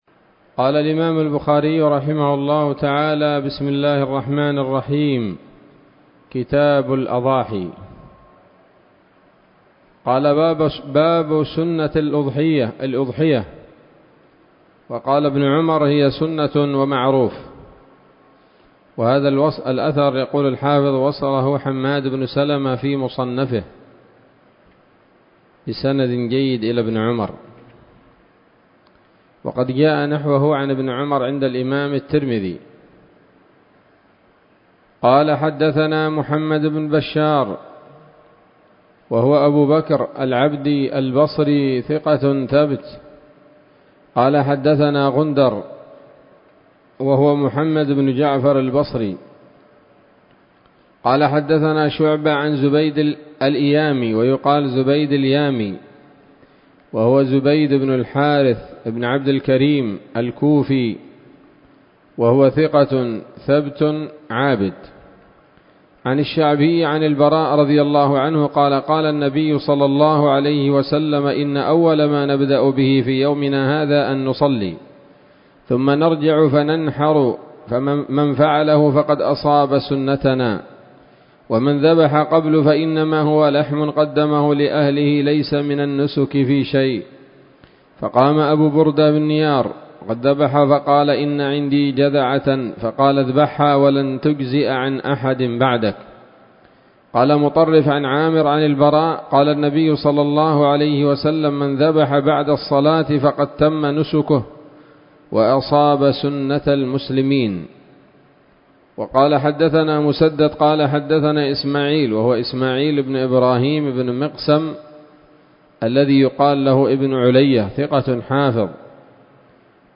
الدرس الأول من كتاب الأضاحي من صحيح الإمام البخاري